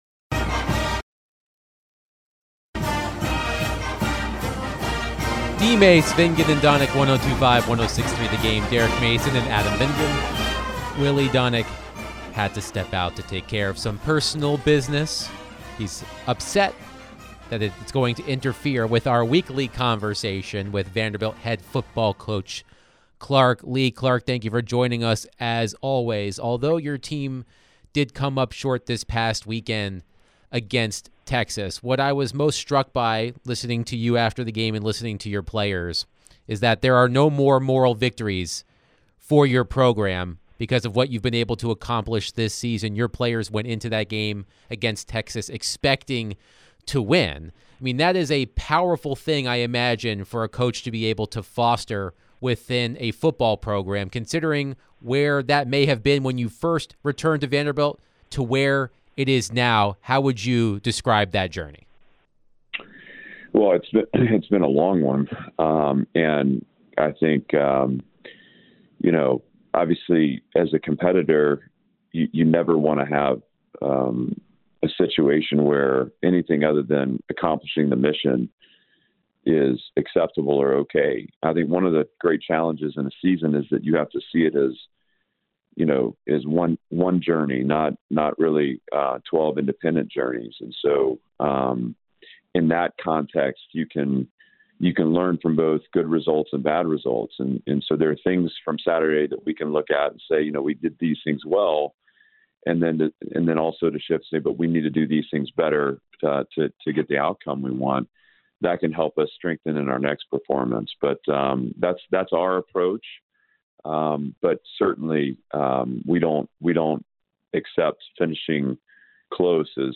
Vanderbilt head football coach Clark Lea joined the show to discuss his team's heartbreaking loss to Texas. What are the Commodores looking to improve on going forward? What does Clark think about the matchup with Auburn?